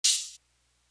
Broomfield Open Hat.wav